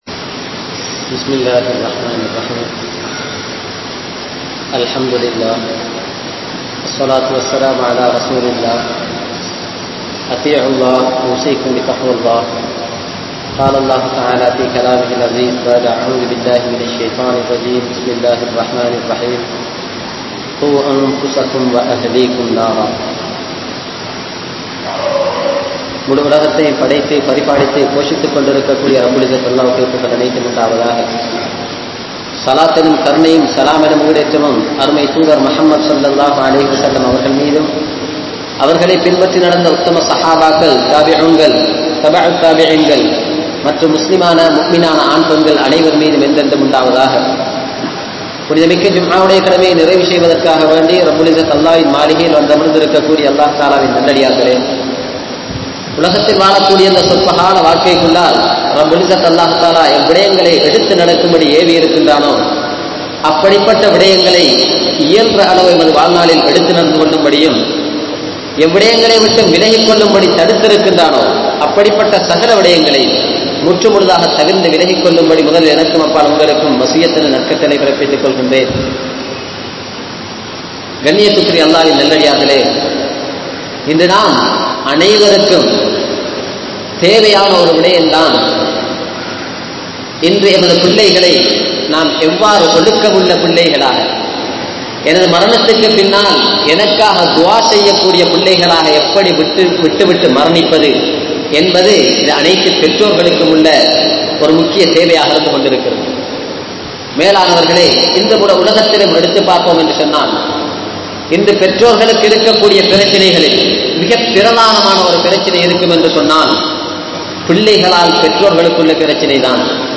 Pettroarhal Muthalil Nallavarhalaaha Vaalungal (பெற்றோர்கள் முதலில் நல்லவர்களாக வாழுங்கள்) | Audio Bayans | All Ceylon Muslim Youth Community | Addalaichenai
Grand Jumua Masjith